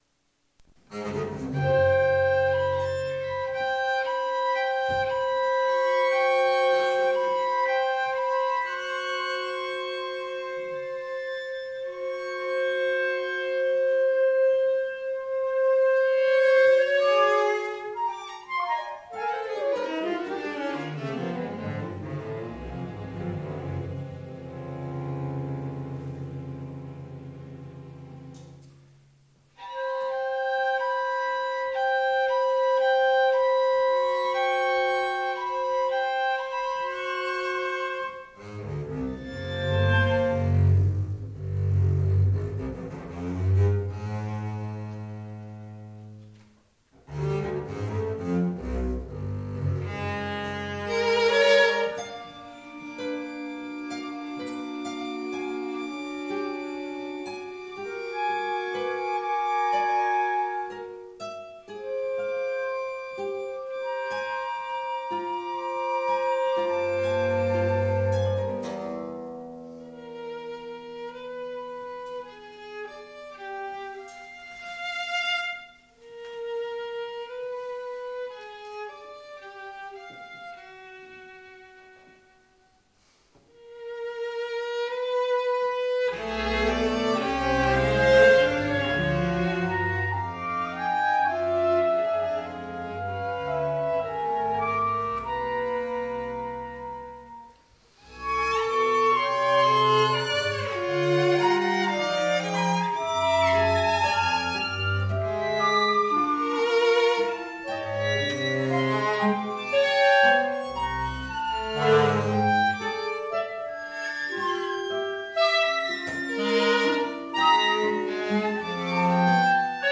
work for ensemble and reciter composed and released in 1994
reciter
flute
clarinet
violin
cello
contrabass
guitar
(live recorded audio)